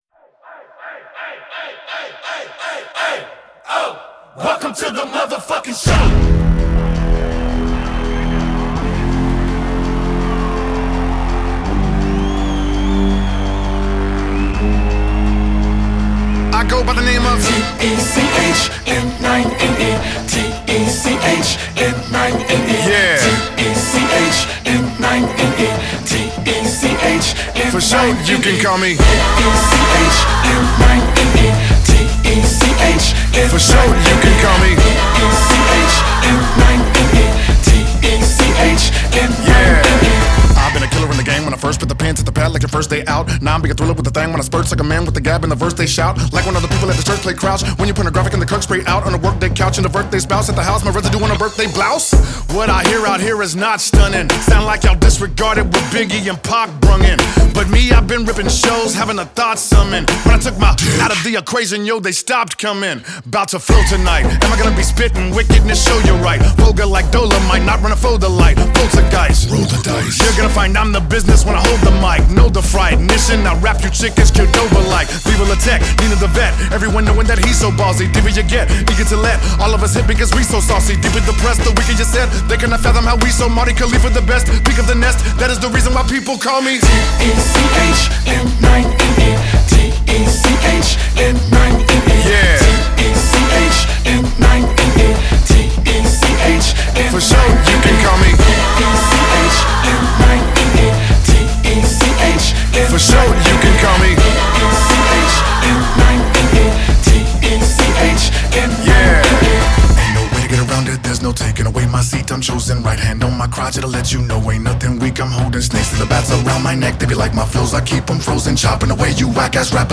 Genre: Rap / hip hop